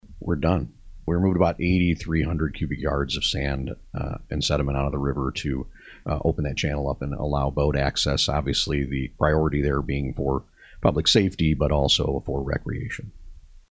During this morning’s meeting of the Kankakee County Board’s Highways, Waterways and Buildings committee